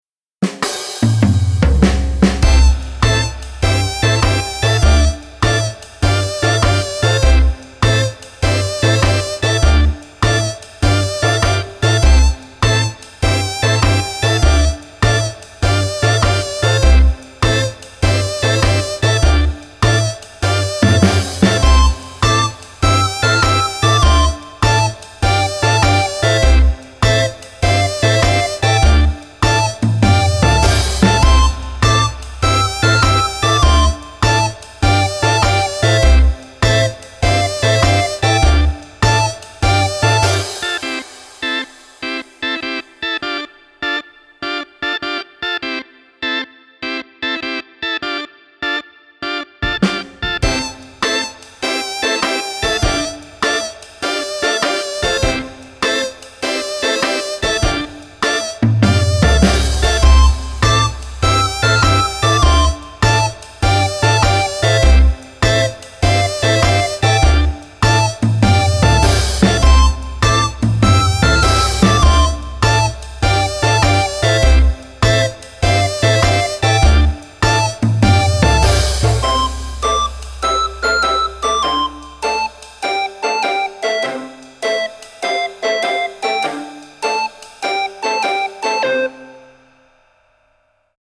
Quirky comical march with synths and organ.